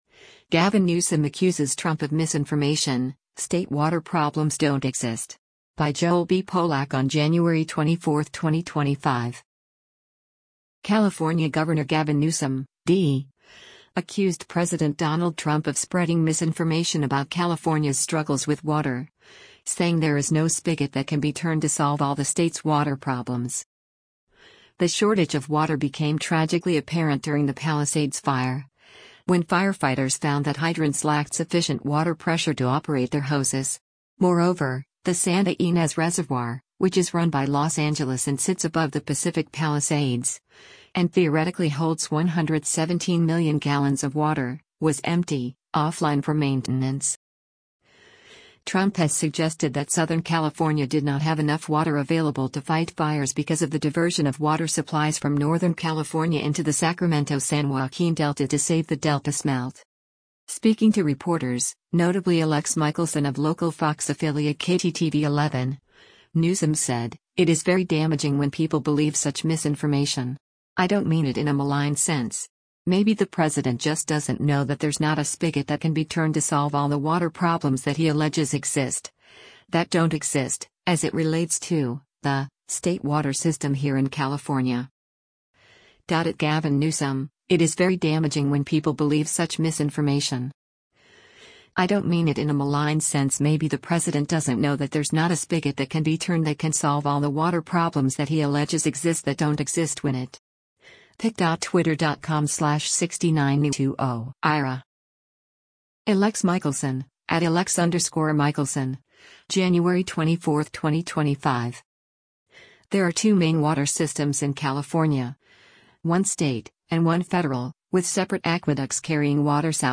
CALIFORNIA, USA - JANUARY 23: Governor Gavin Newsom answers questions after a press confer